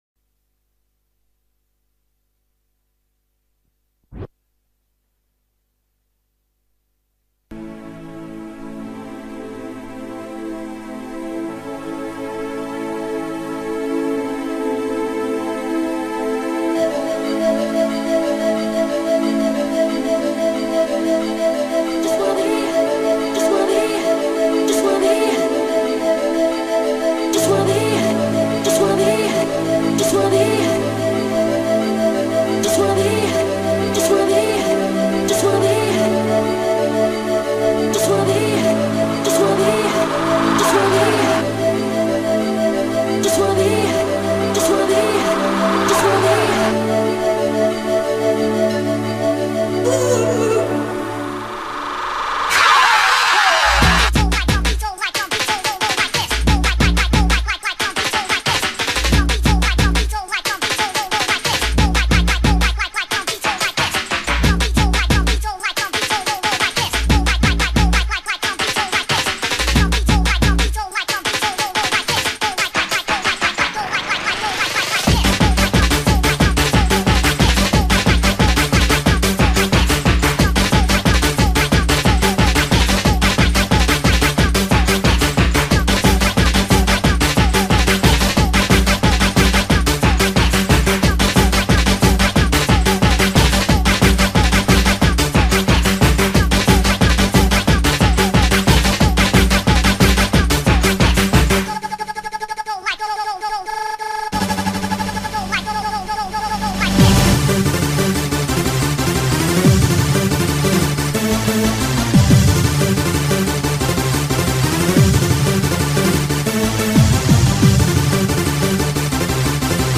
Vocals Oldskool Happy Hardcore